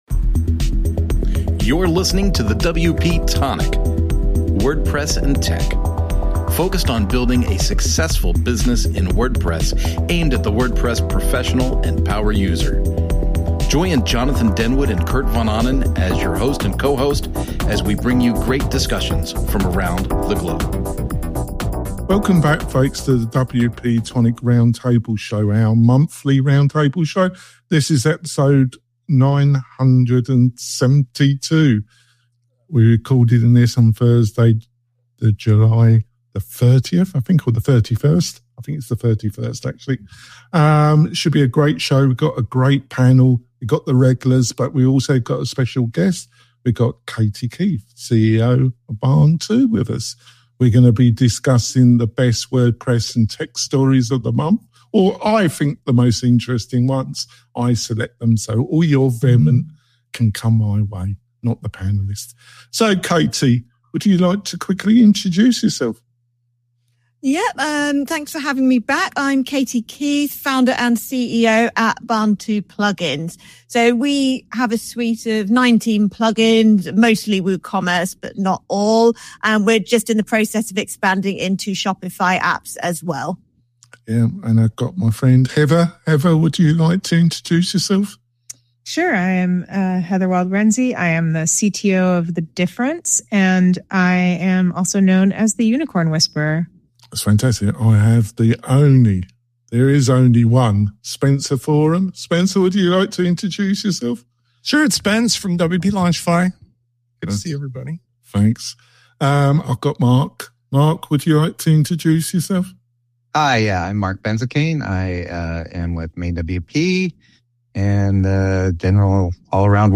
#972- WP-Tonic This Month in WordPress & Tech Round Table Show For July, 2025